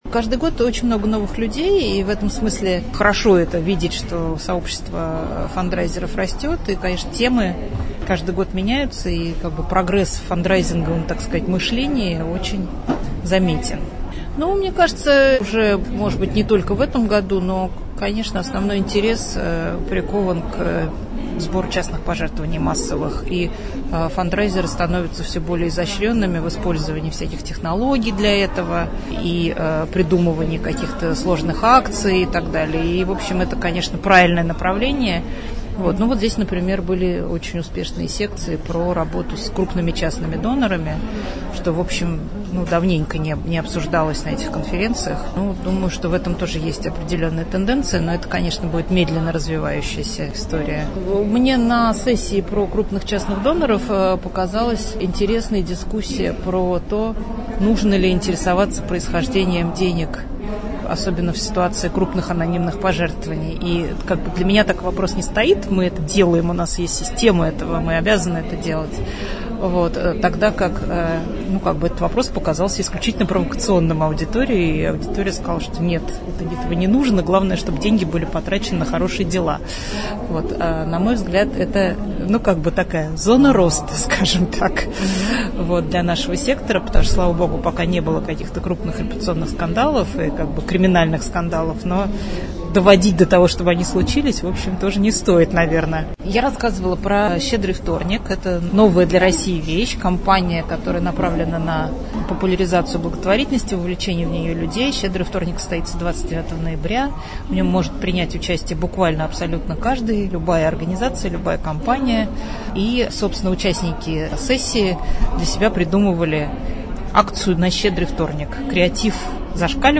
«Расскажем» — аудиопроект Агентства социальной информации: живые комментарии экспертов некоммерческого сектора на актуальные темы.